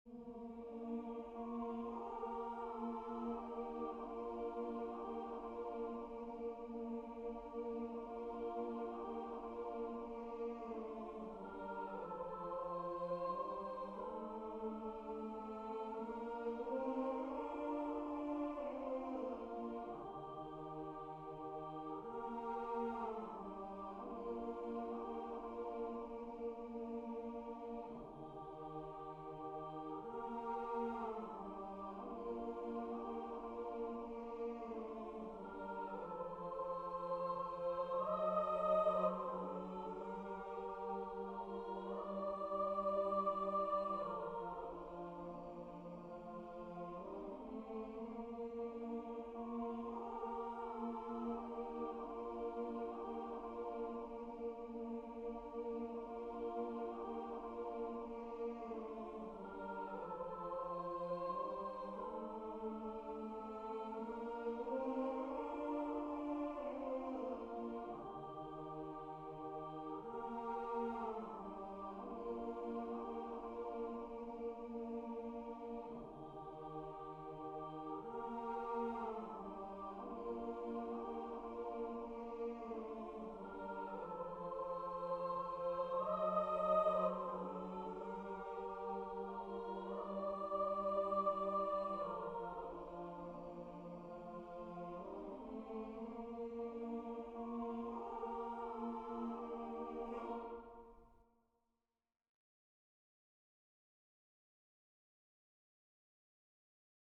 Formación:coro SAB
Género:Canción popular / Nadala